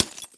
hit_glass.mp3